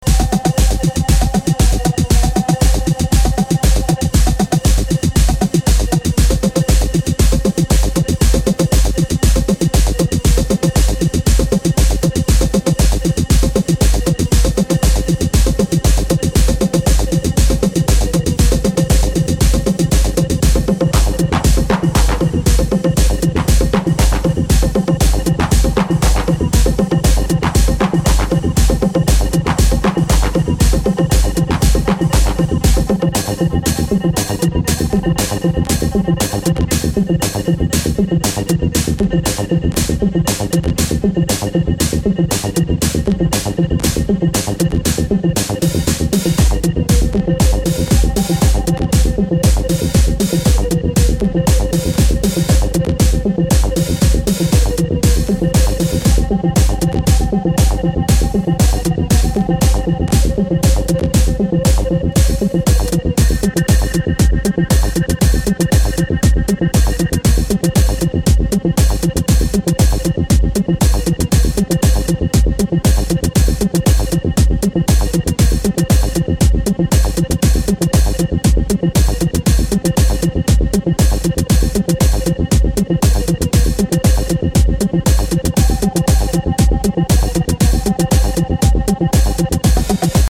HOUSE/BROKEN BEAT